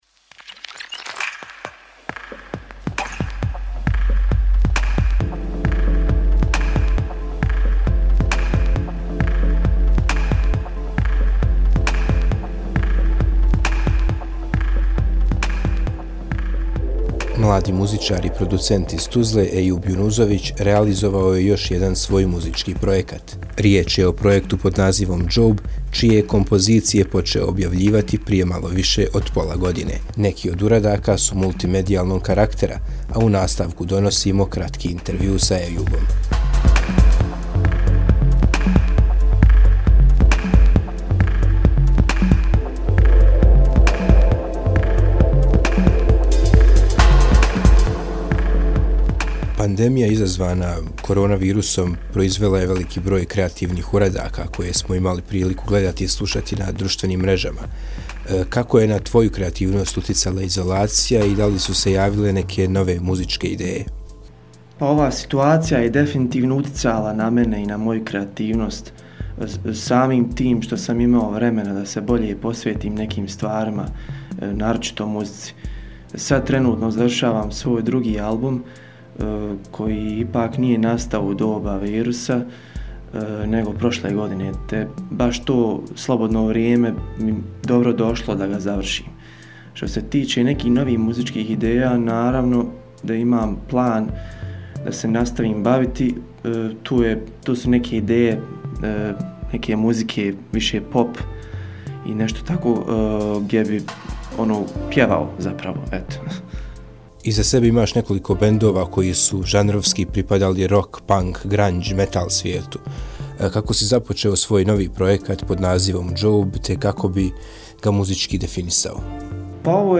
kratki intervju